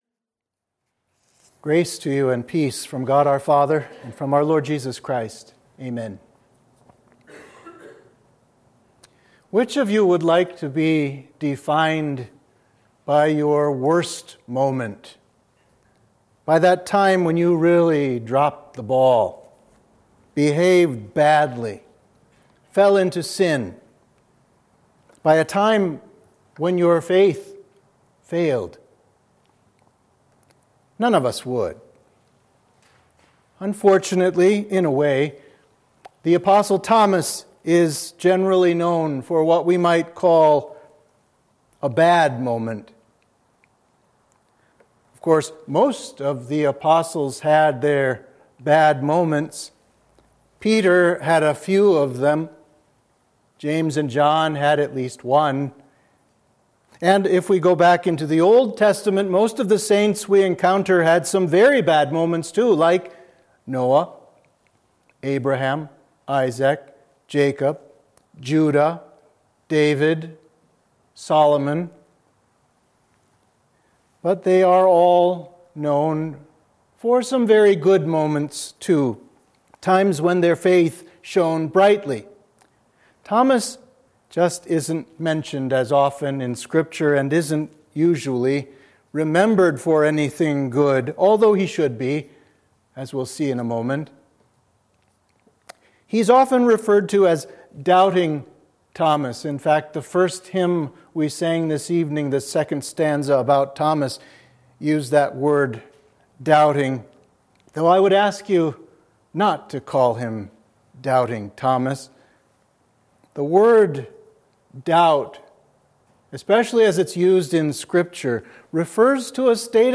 Sermon for Midweek of Advent 4 – St. Thomas